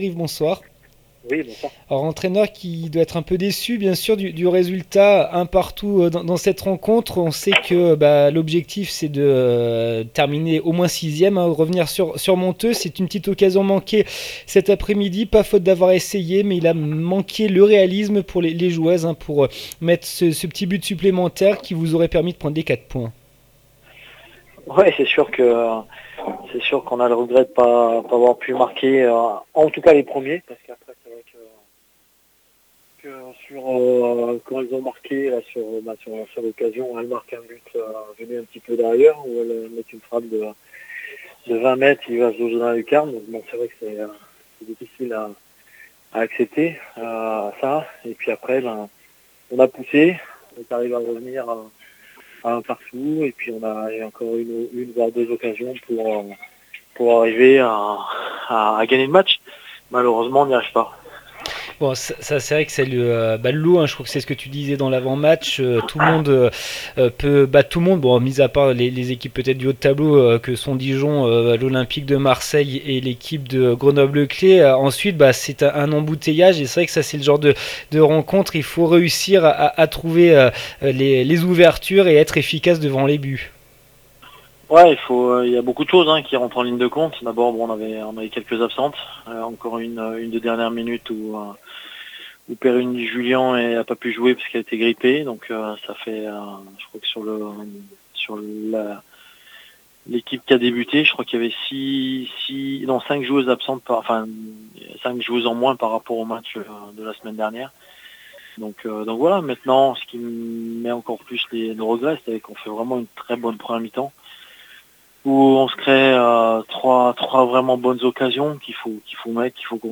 29 février 2016   1 - Vos interviews   No comments